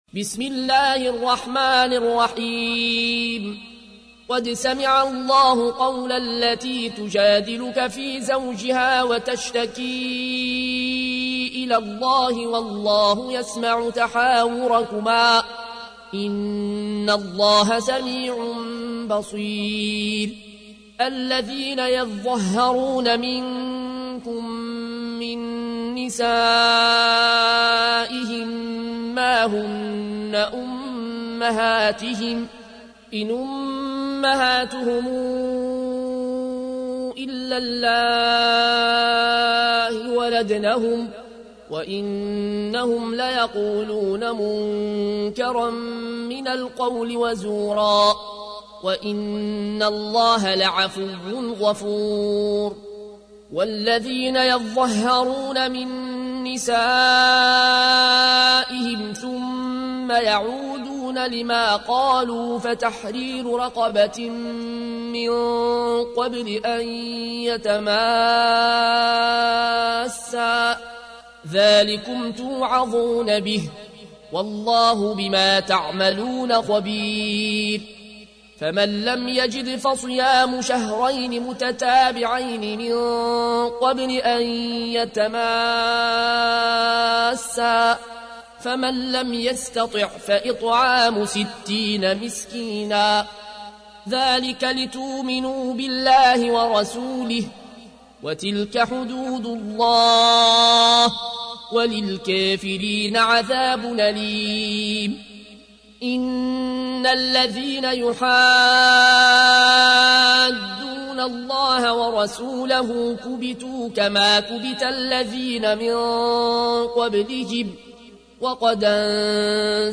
تحميل : 58. سورة المجادلة / القارئ العيون الكوشي / القرآن الكريم / موقع يا حسين